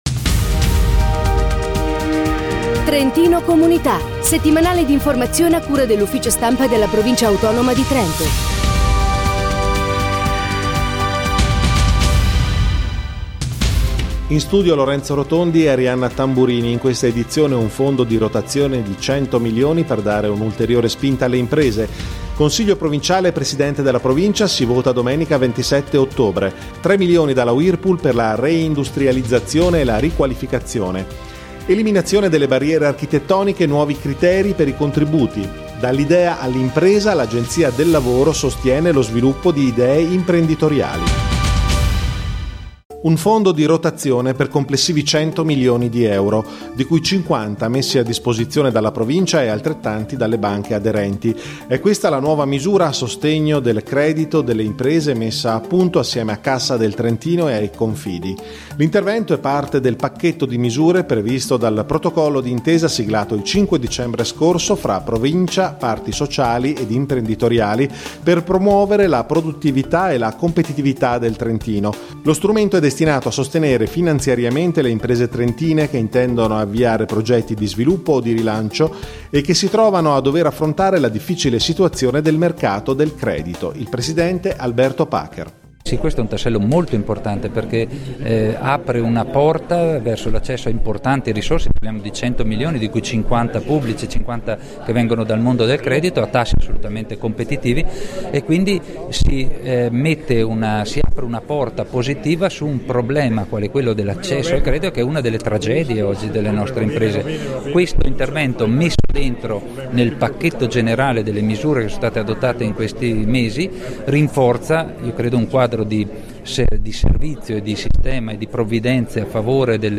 Notiziario